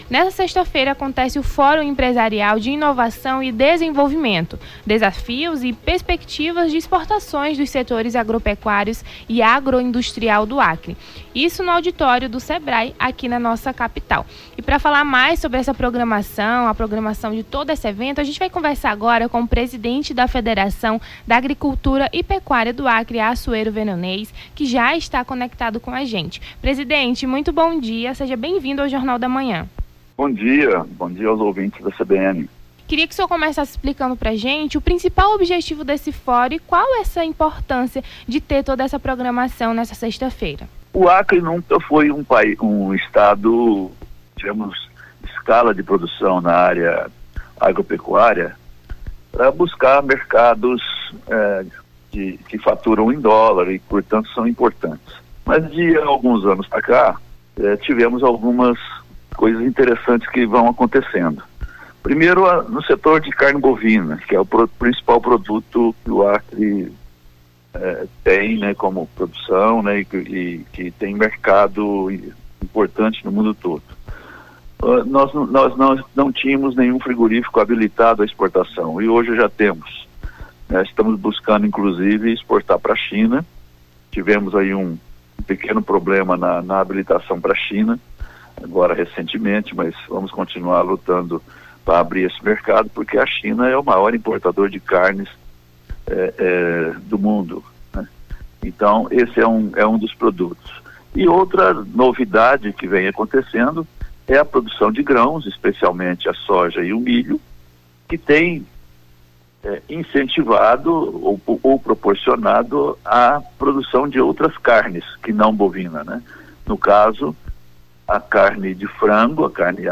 Nome do Artista - CENSURA - ENTREVISTA DESAFIOS AGROPECUÁRIOS (04-04-24).mp3